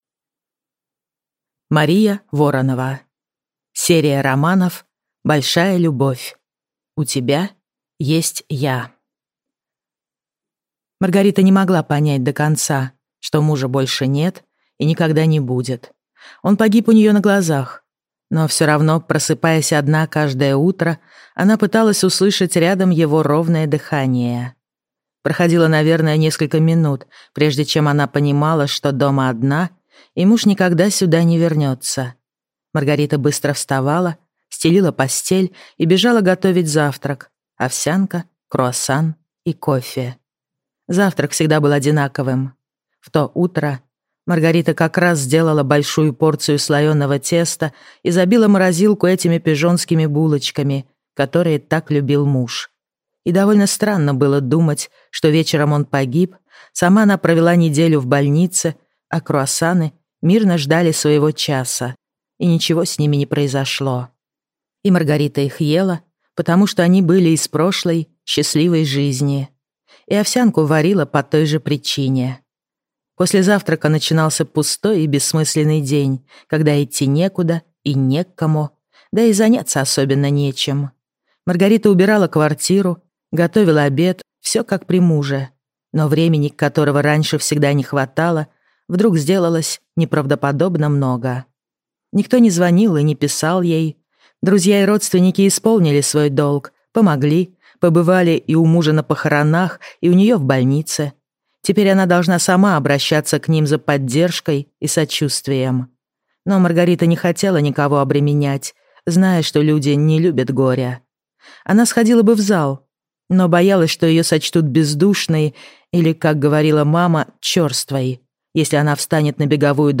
Аудиокнига У тебя есть я | Библиотека аудиокниг